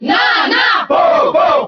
Category:Crowd cheers (SSBB) You cannot overwrite this file.
Ice_Climbers_Cheer_French_SSBB.ogg.mp3